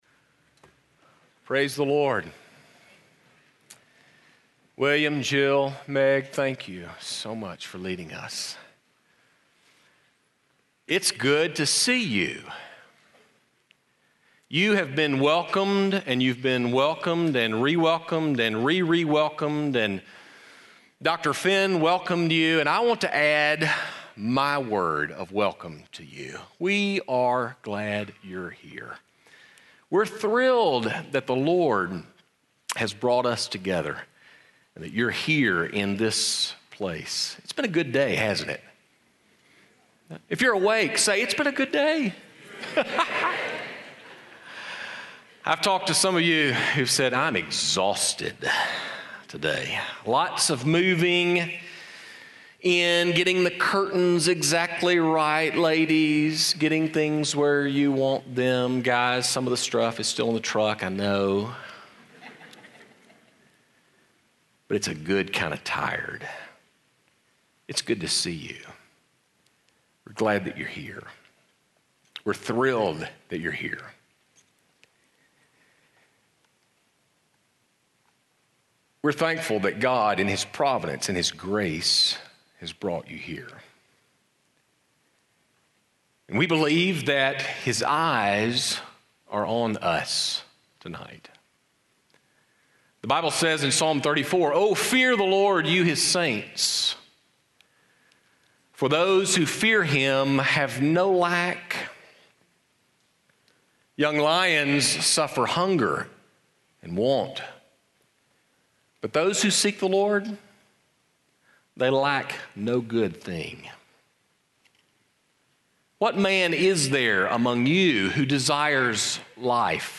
Welcome Week Chapel